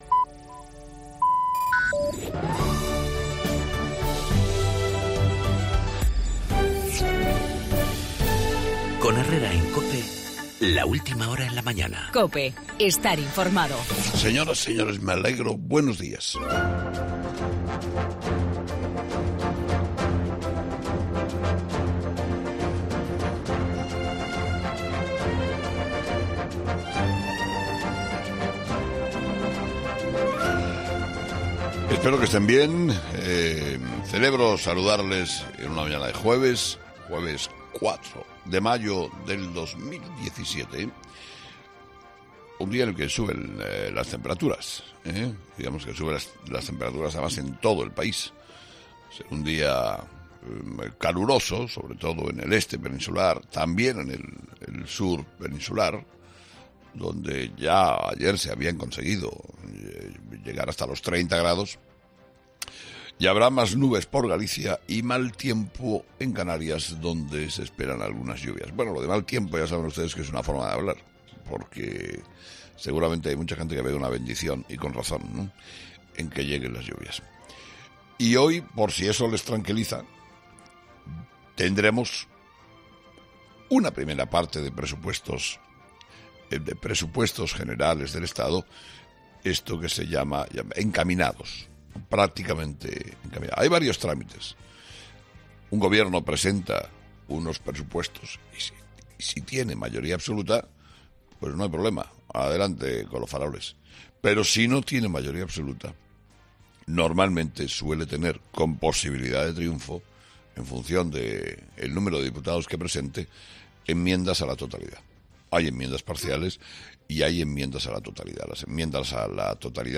Escucha el Monólogo de 'Herrera a las 8'